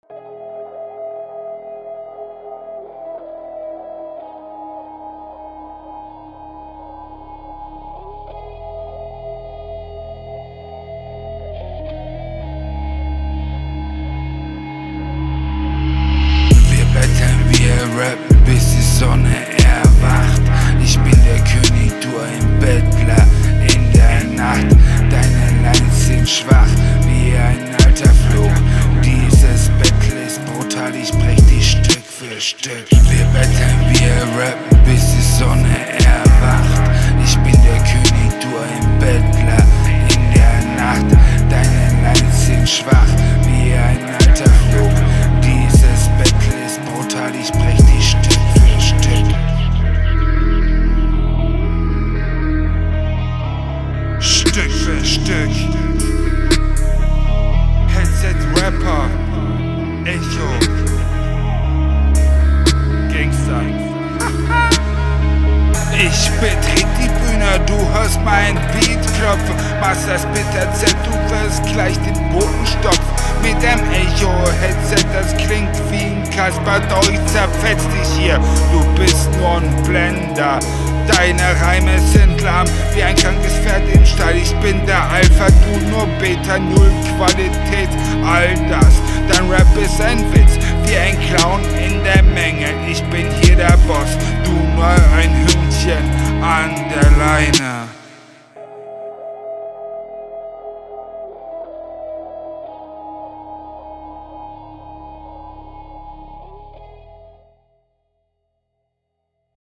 guter... beat ich mag den beat sehr also der beatpick ist schon cool produzent hat …